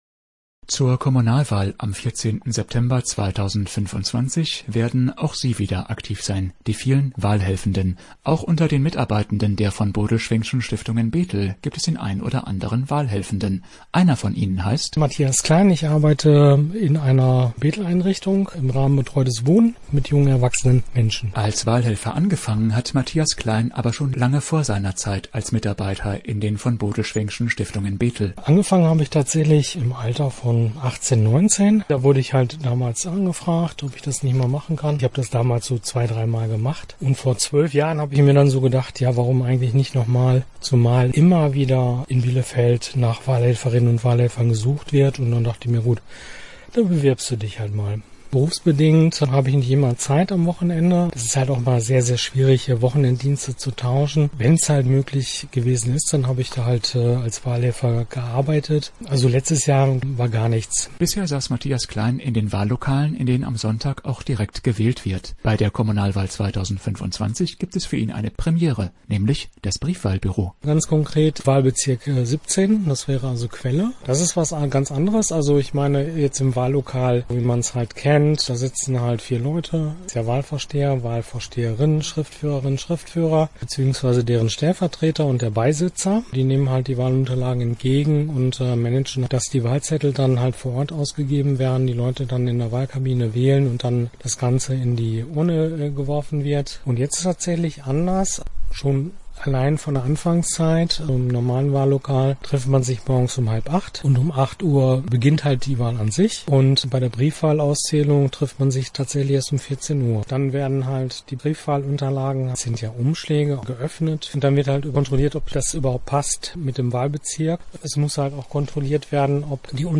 Wahlhelfer-Potrait.mp3